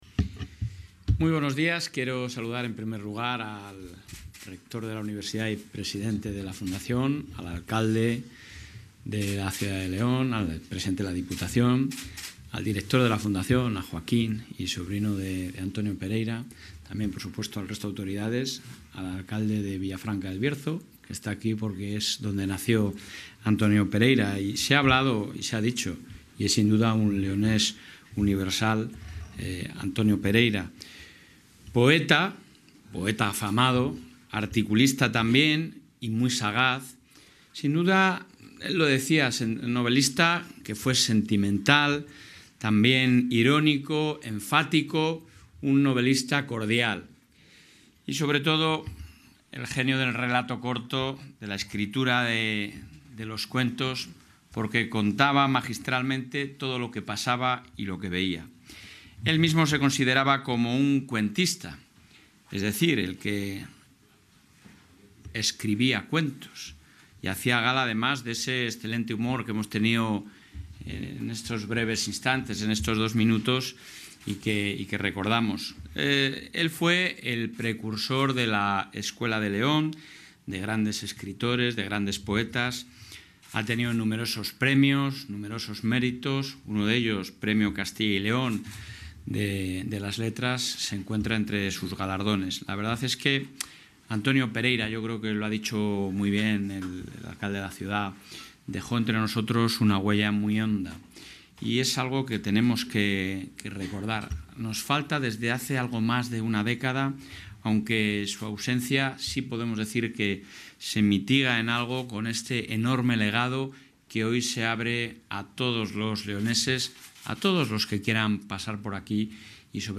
Intervención del presidente de la Junta.